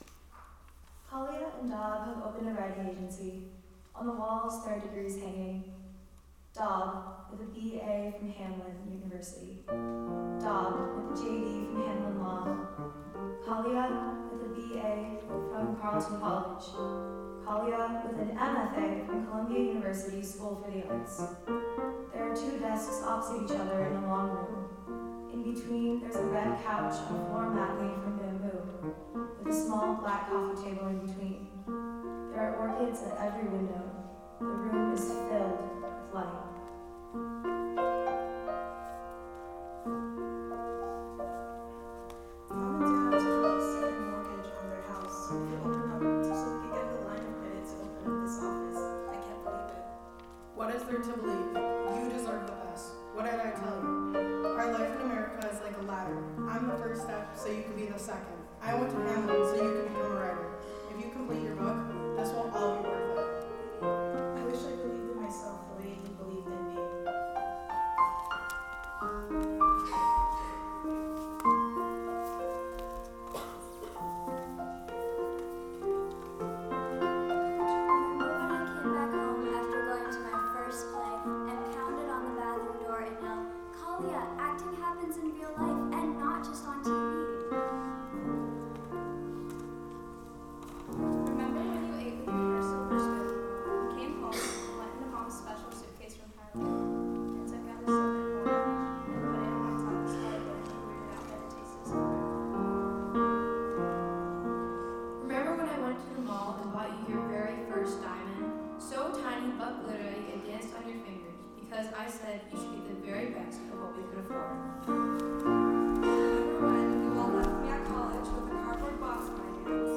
2-part treble choir and piano